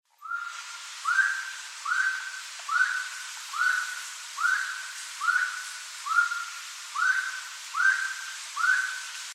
Saracura (Aramides saracura)
Nombre en inglés: Slaty-breasted Wood Rail
Fase de la vida: Adulto
Localidad o área protegida: Reserva Privada y Ecolodge Surucuá
Condición: Silvestre
Certeza: Vocalización Grabada
SARACURA.MP3